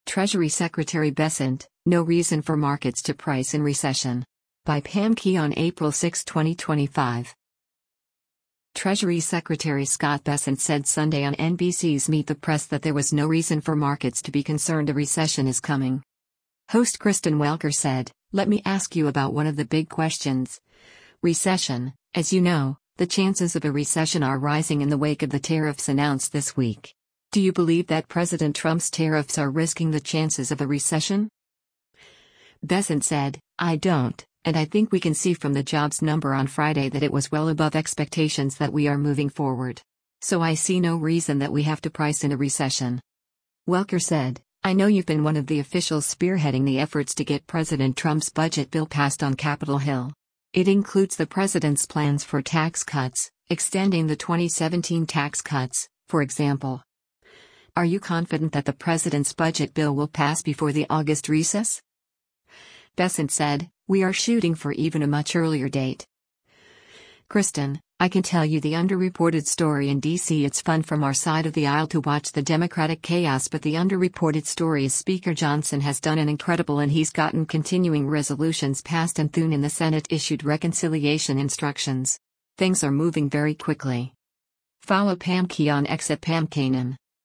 Treasury Secretary Scott Bessent said Sunday on NBC’s “Meet the Press” that there was “no reason” for markets to be concerned a recession is coming.